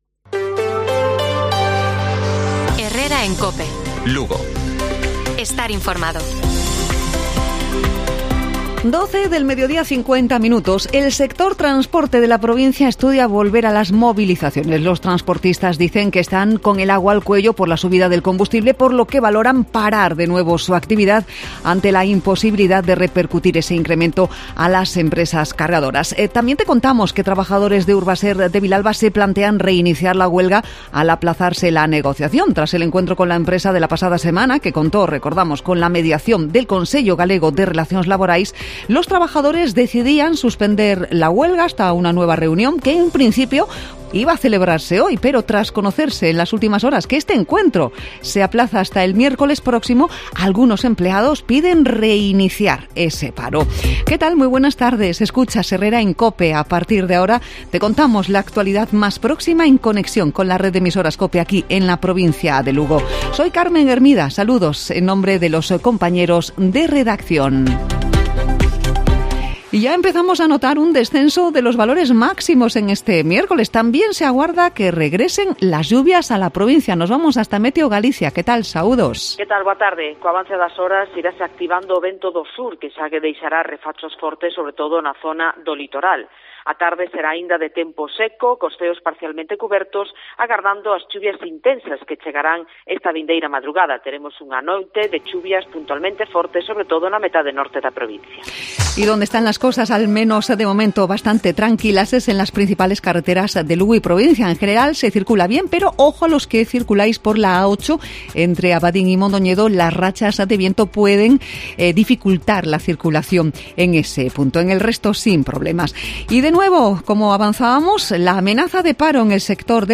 Informativo Provincial de Cope Lugo.